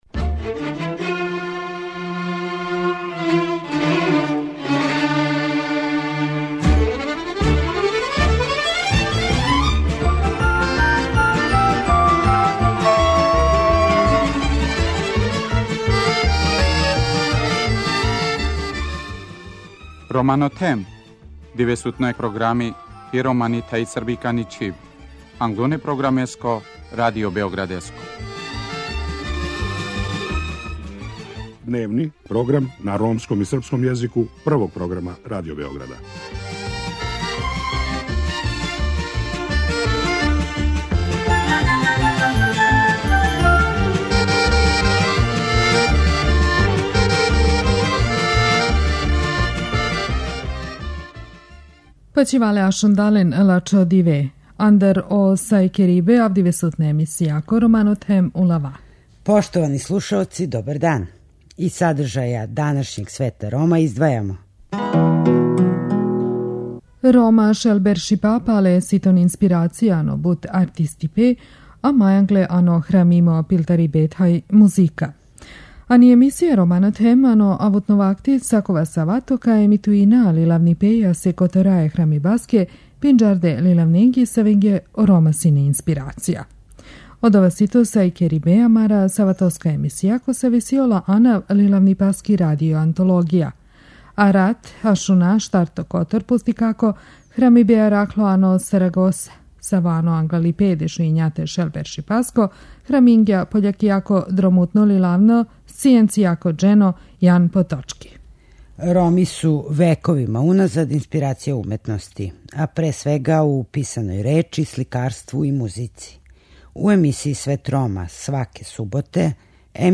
Вечерас слушамо наставак књижевног одломка из дела "Рукопис нађен у Сарагоси", који је почетком 19. века написао пољски путописац, научник и приповедач Јан Потоцки.